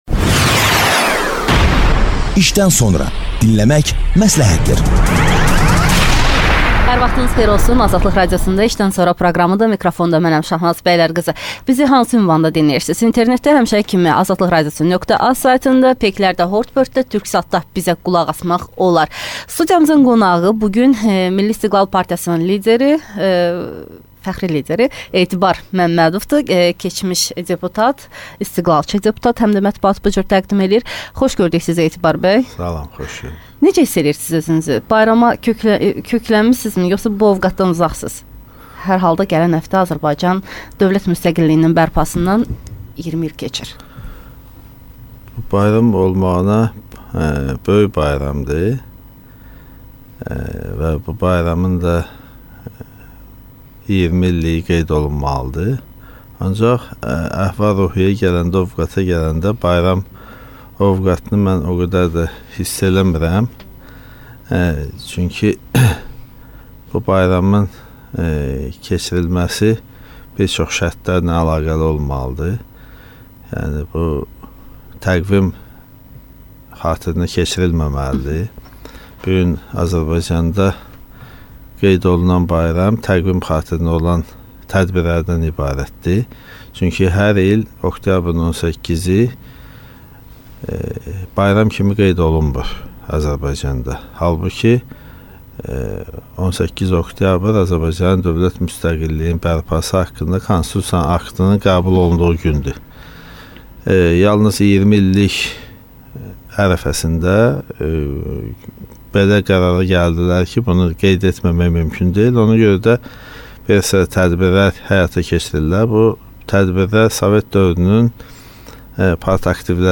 «Müstəqilliyin 20 illiyi» silsiləsindən istiqlalçı deputat Etibar Məmmədovun «İşdən sonra» proqramına müsahibəsindən bəzi sitatları nəzərinizə çatdırırıq